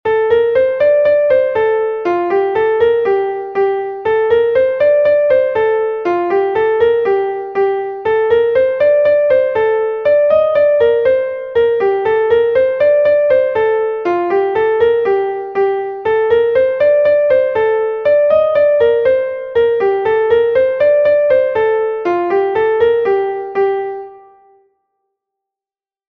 a Gavotte from Brittany